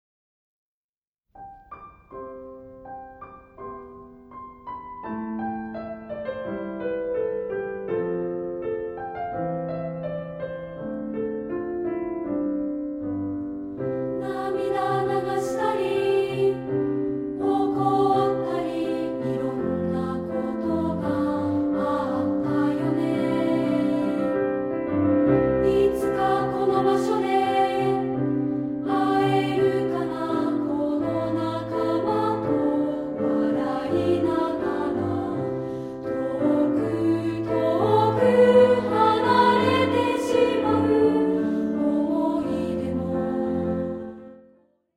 範唱＋カラピアノCD付き
2部合唱／伴奏：ピアノ